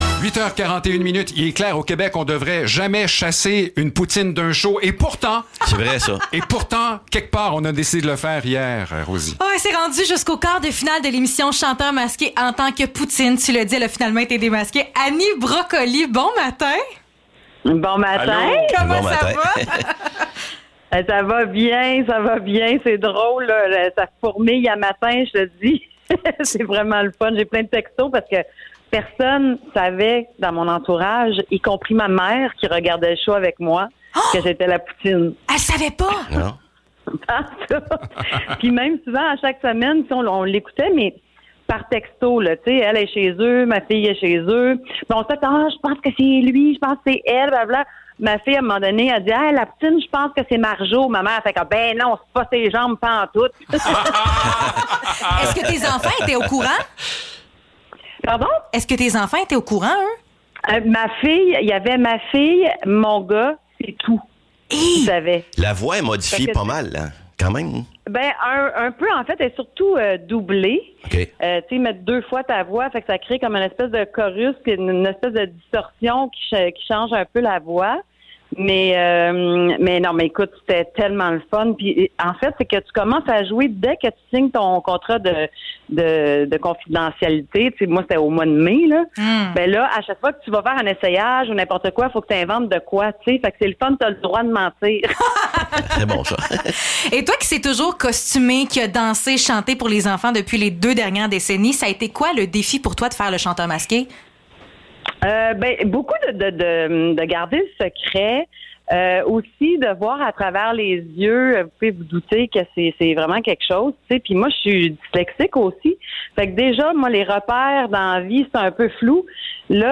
Entrevue avec Annie Brocoli (15 novembre 2021)